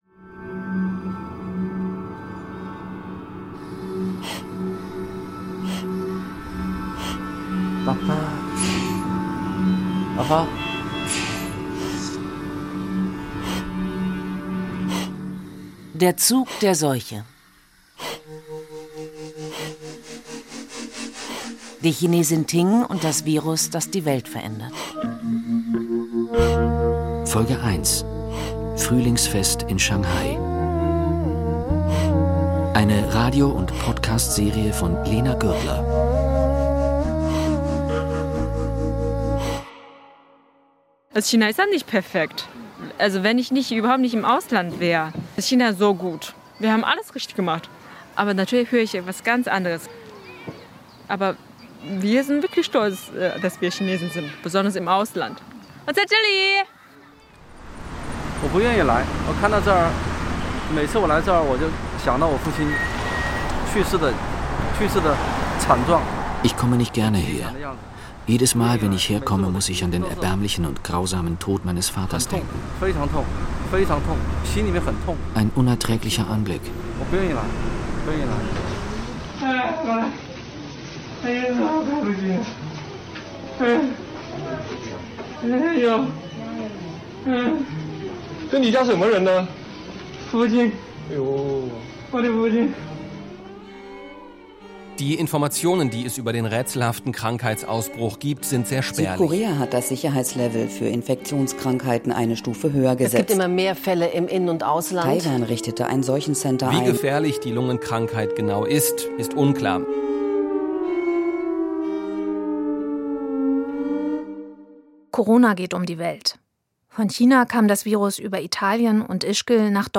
Doch sie ahnt nicht, dass sich zu diesem Zeitpunkt das Coronavirus längst verbreitet, von Wuhan aus in die ganze Welt. Feature